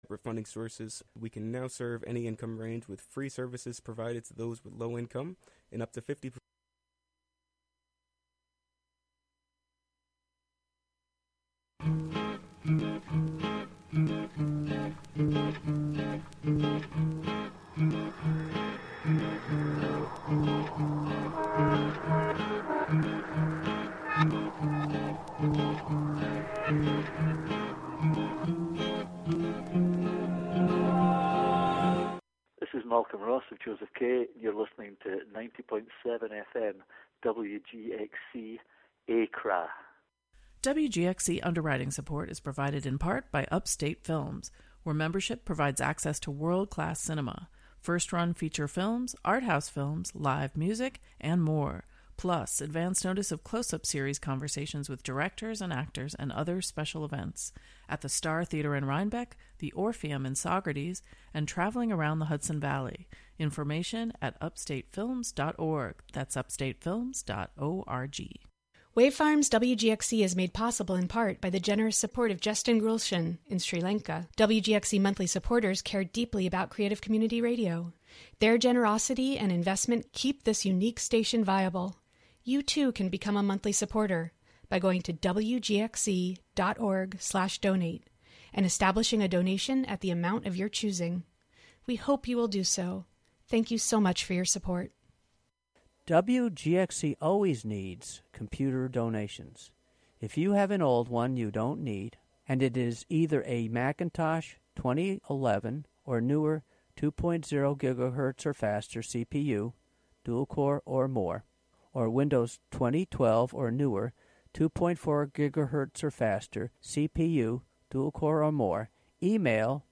Live from the Catskill Makers space on W. Bridge St. in Catskill, a monthly show about science, technology, fixing, making, hacking, and breaking with the amorphous collection of brains comprising the "Skill Syndicate."